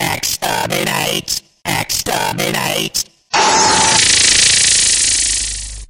Dalek (exterminate-beamsound)
exterminate_1.mp3